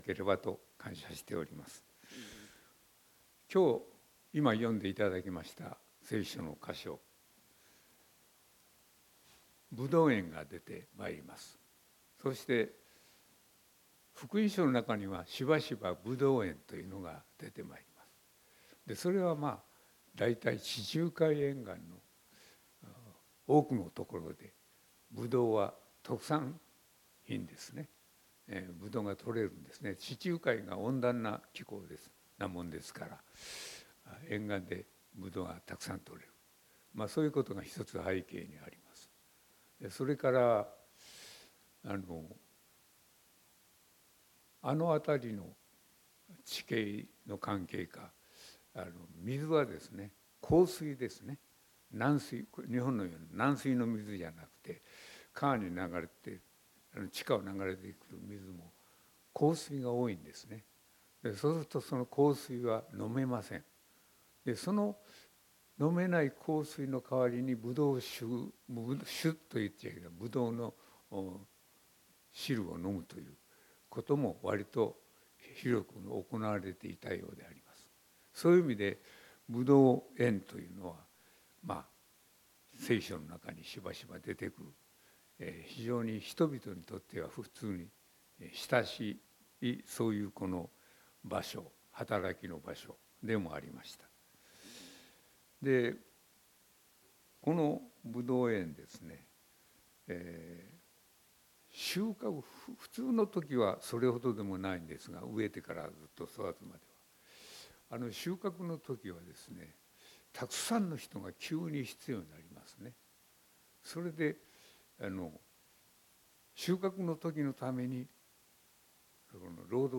長年勤められた先生からいただいた信仰に関する言葉には、たいへん重みがあり、心に残る説教となりましたことを心から感謝いたしております。